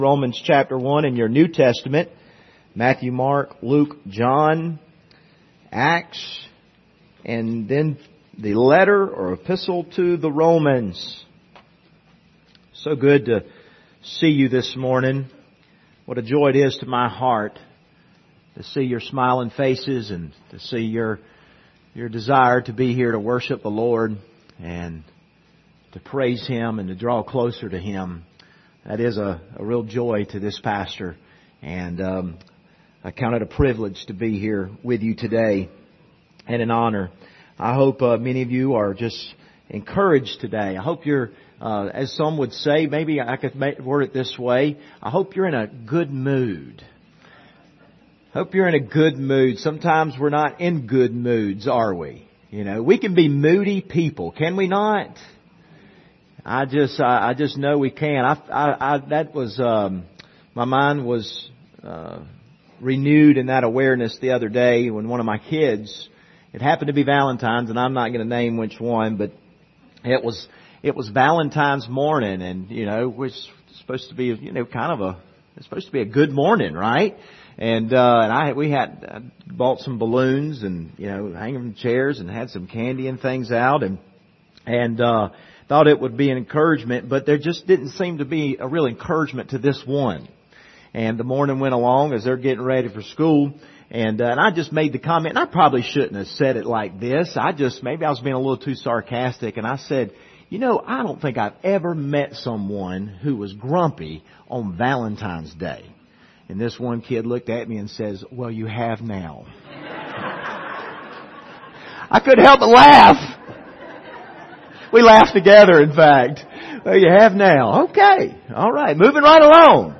Passage: Romans 1:1 Service Type: Sunday Morning